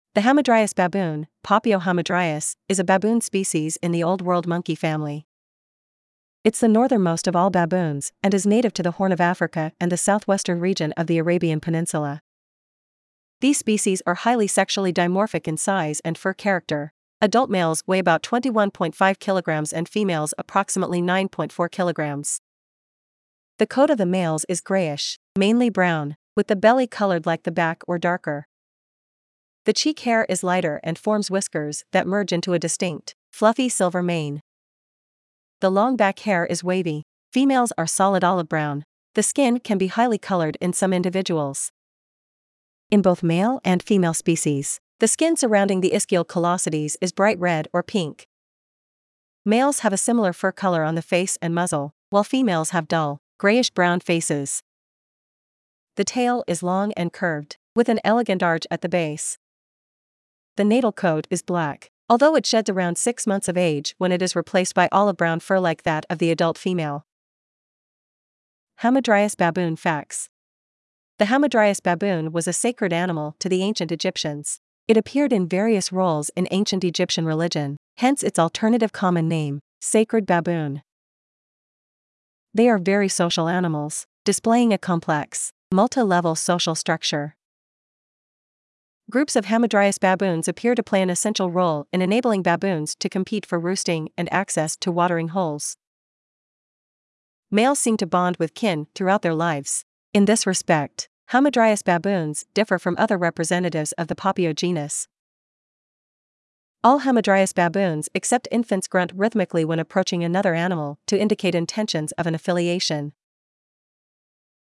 Hamadryas Baboon
• All hamadryas baboons except infants grunt rhythmically when approaching another animal to indicate intentions of an affiliation.
Hamadryas-baboon.mp3